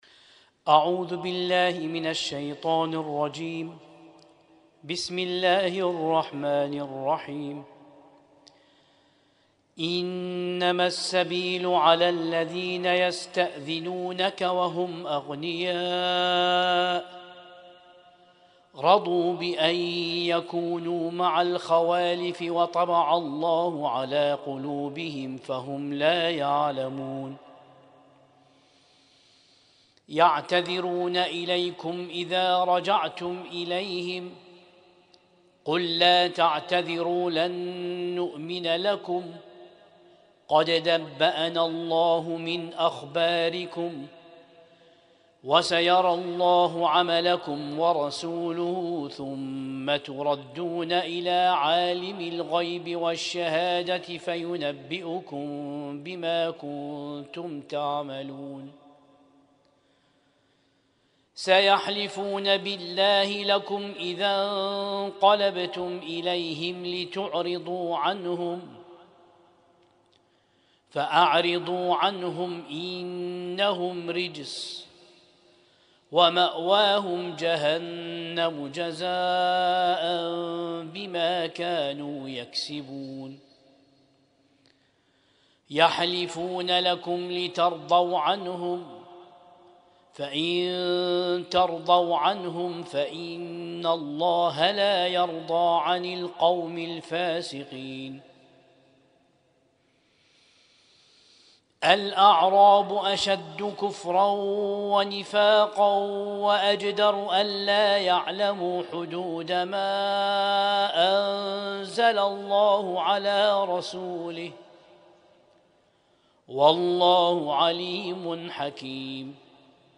ليلة 11 من شهر رمضان المبارك 1447هـ